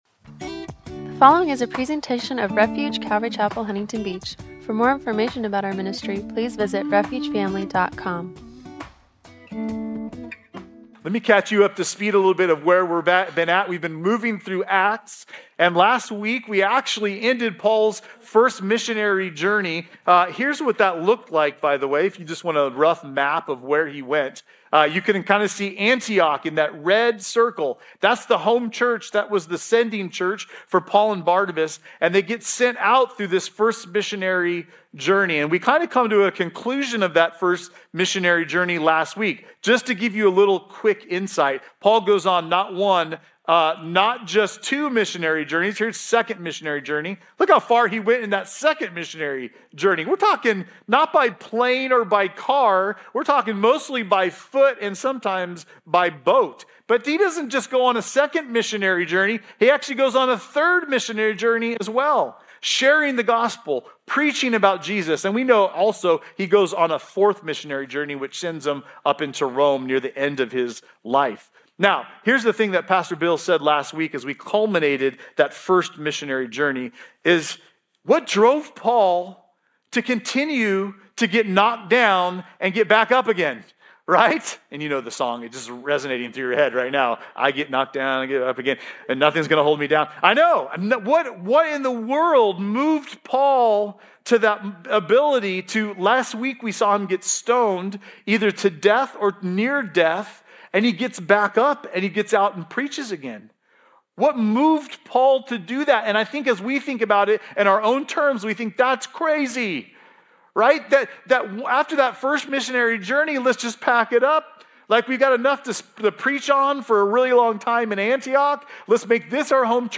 Part 1”-Acts 14:12-15:11 – Audio-only Sermon Archive